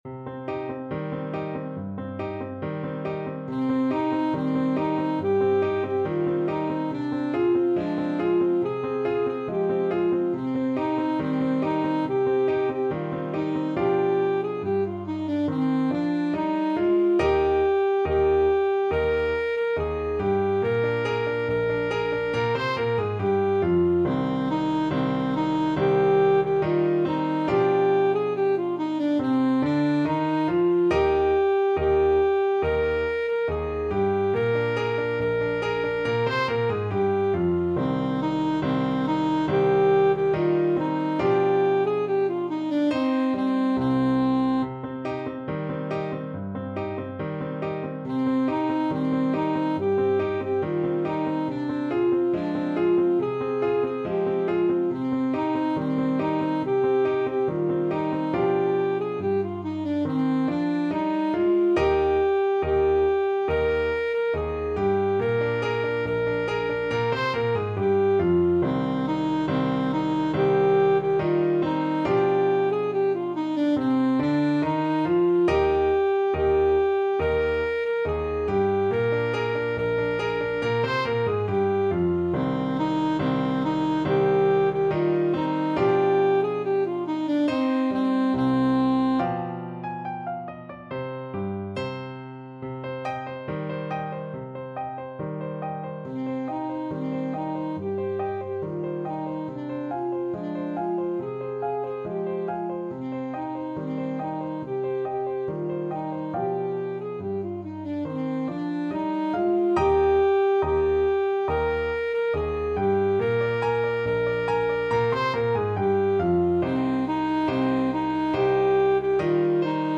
Alto Saxophone version
Alto Saxophone
4/4 (View more 4/4 Music)
C minor (Sounding Pitch) A minor (Alto Saxophone in Eb) (View more C minor Music for Saxophone )
Traditional (View more Traditional Saxophone Music)
world (View more world Saxophone Music)